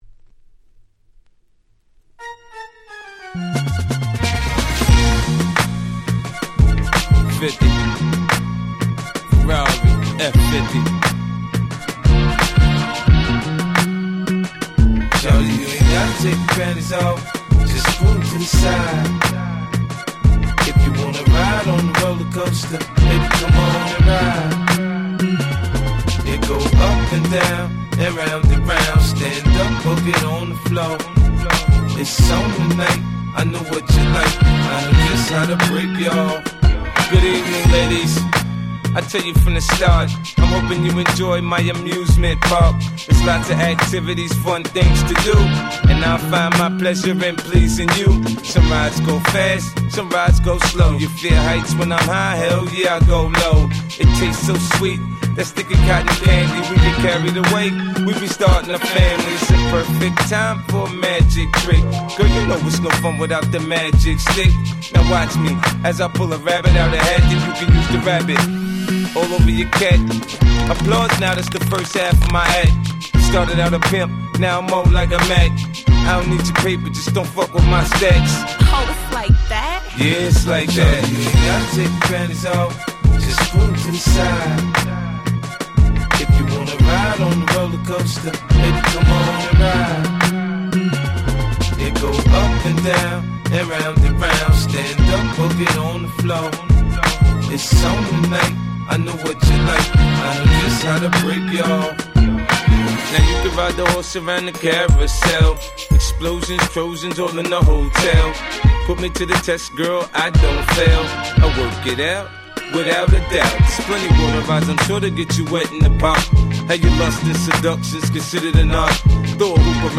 【Media】Vinyl 12'' Single (Promo)
07' Smash Hit Hip Hop !!